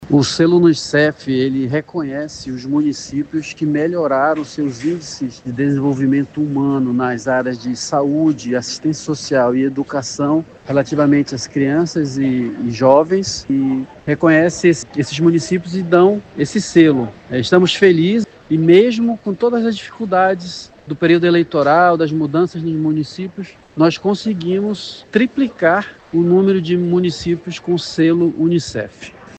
Entre os indicadores que melhoraram no Amazonas estão a cobertura vacinal, que cresceu 8,8% nos 28 municípios certificados, e a redução do abandono escolar, que caiu 39,3%, superando a média nacional, como explica o Secretário de Estado de Desenvolvimento Urbano e Metropolitano (Sedurb), Marcellus Câmpelo.